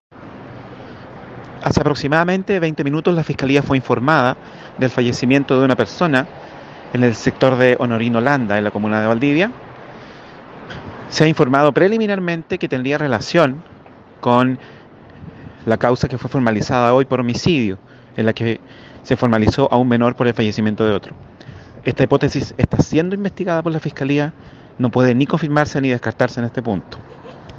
Fiscal…